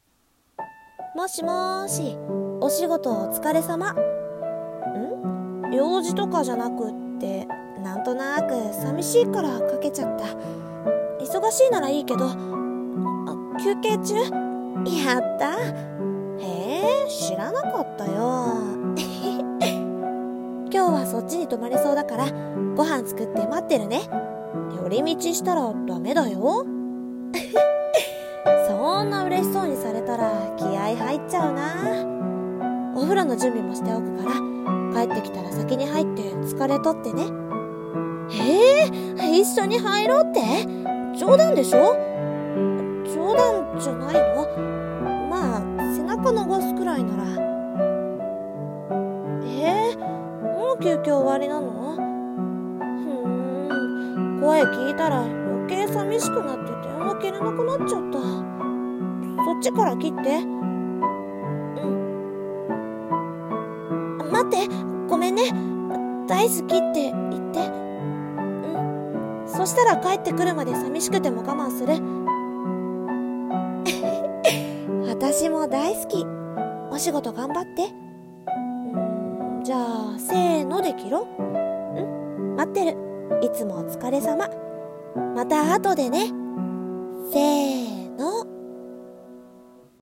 [声劇]嬉しい電話【１人声劇】